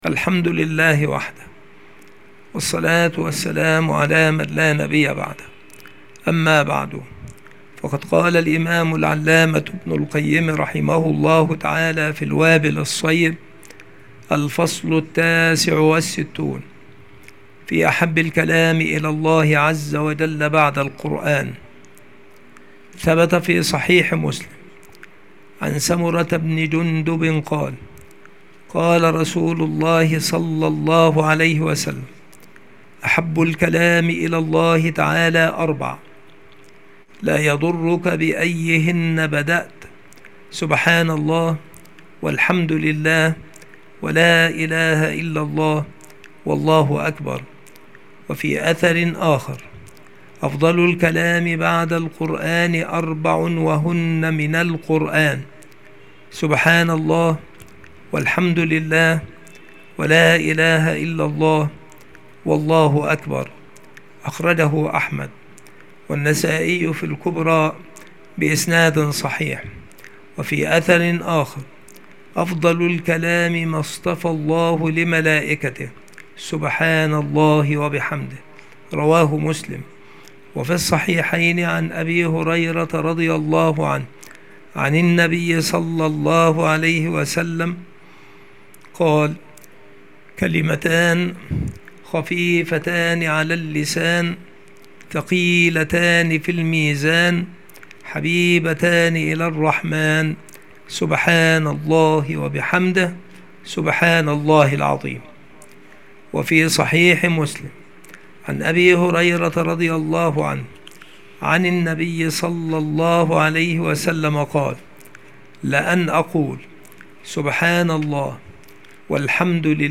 مكان إلقاء هذه المحاضرة المكتبة - سبك الأحد - أشمون - محافظة المنوفية - مصر